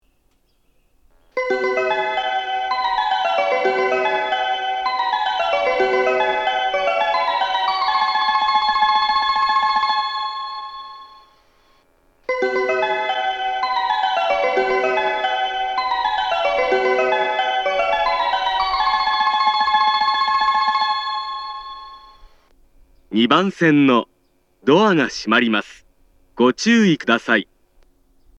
スピーカーが上下兼用なので、交換のある列車の場合、放送が被りやすいです。
発車メロディー
1.9コーラスです。